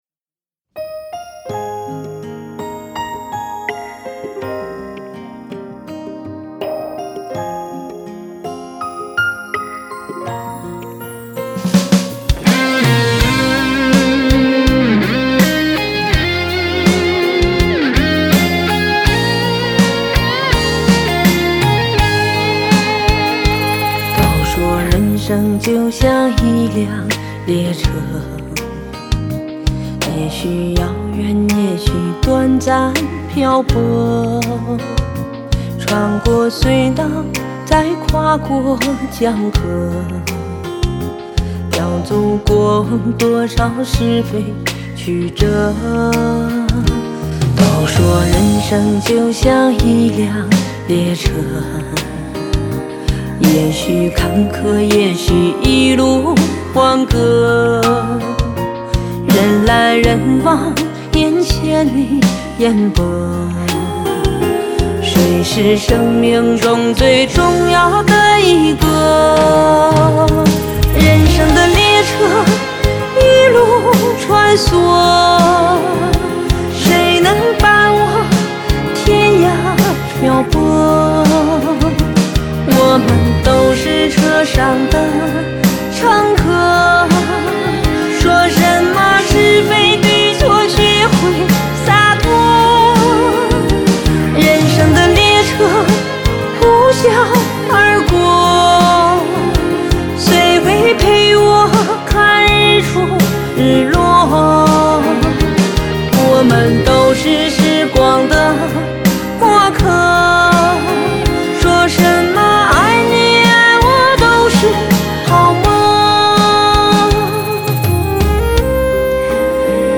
吉他
键盘
和声